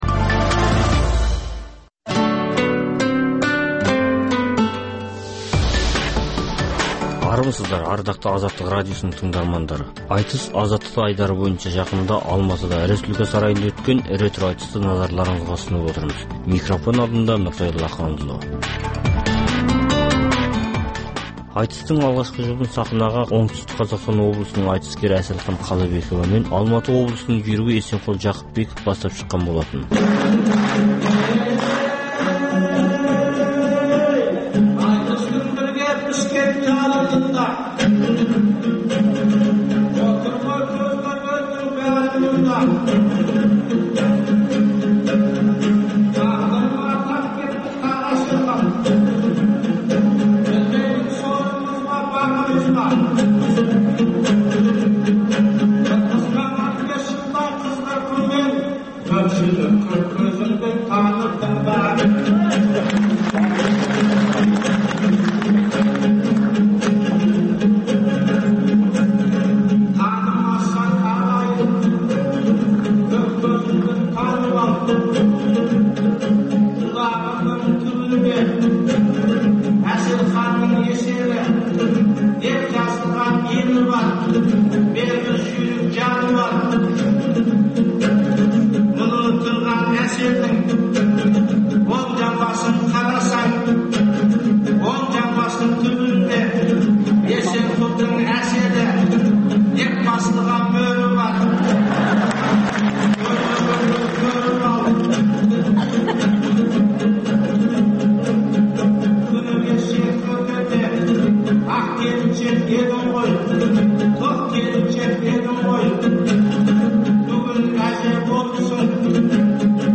Пікірталас клубы